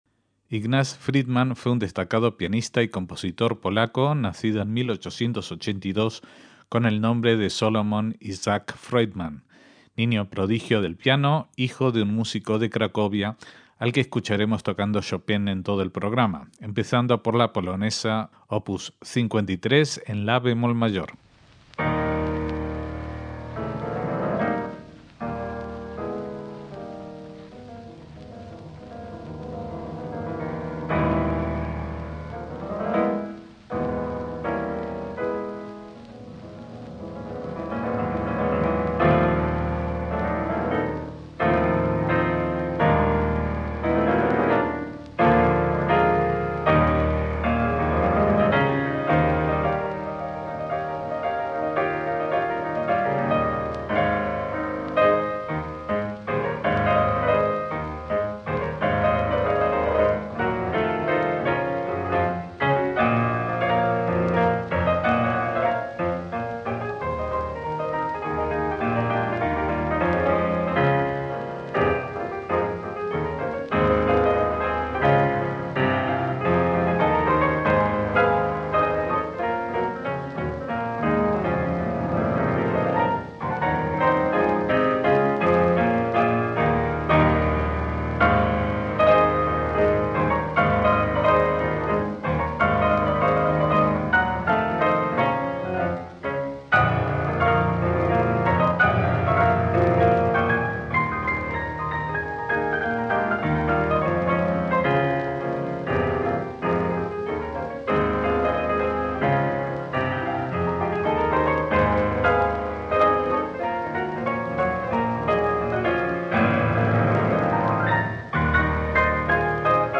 MÚSICA CLÁSICA - Ignaz (Salomón Isaac) Friedman fue un pianista y compositor judío polaco, virtuoso de la época de oro junro a otros maestros del teclado como Godowsky, Rosenthal, Hofmann y Lhévinne.
Su estilo era tranquilo y destacó especialmente por sus interpretaciones de Chopin, como las que traemos en este programa.